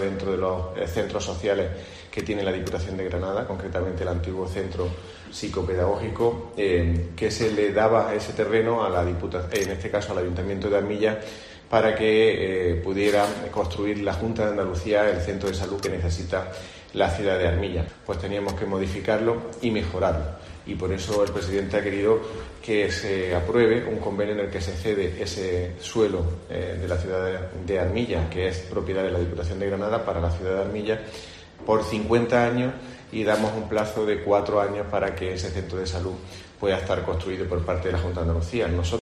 Nicolás Navarro, Portavoz del Gobierno de la Diputación